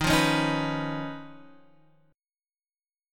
D#7sus2#5 Chord